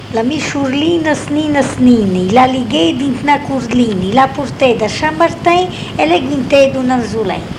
filastrocca - la mi surlina znina znina.mp3